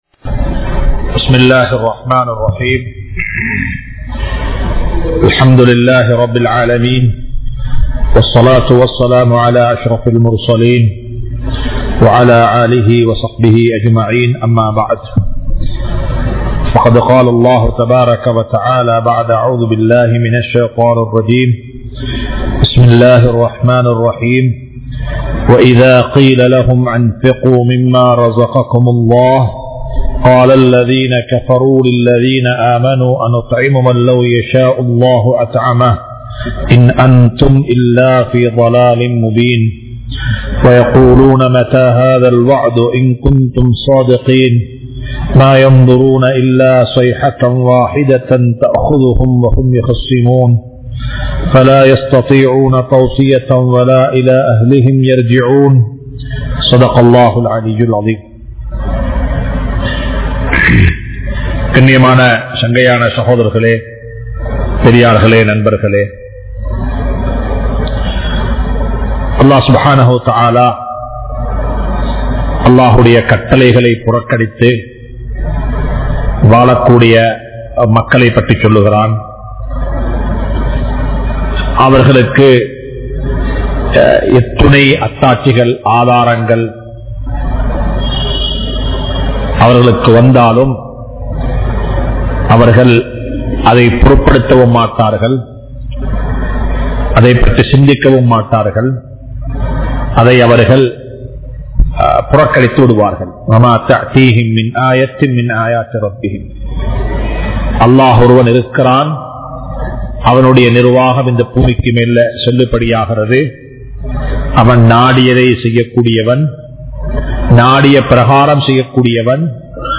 Nilamaihalai Maattrufavan Allah(Surah Yaseen 198) (நிலமைகளை மாற்றுபவன் அல்லாஹ்) | Audio Bayans | All Ceylon Muslim Youth Community | Addalaichenai
Majma Ul Khairah Jumua Masjith (Nimal Road)